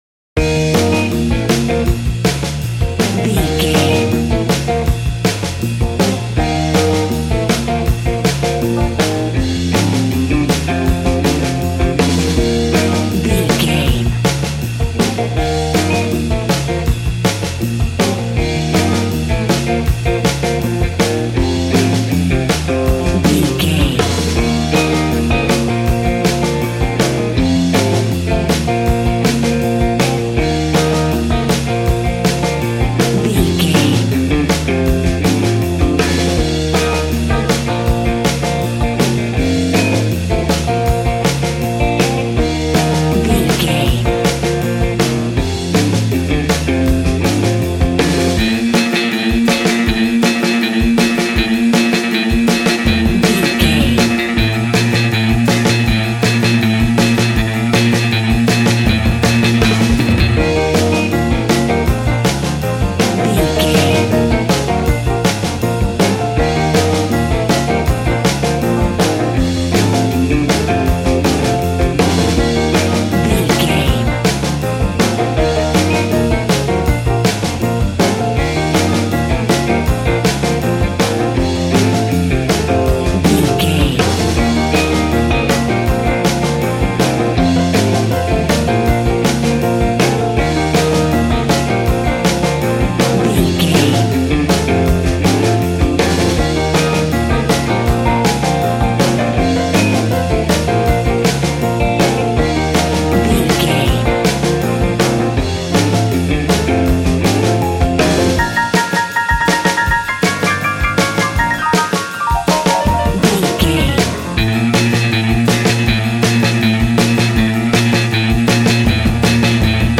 Ionian/Major
B♭
cheerful/happy
double bass
drums
piano